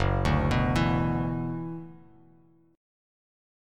Gb7sus4#5 chord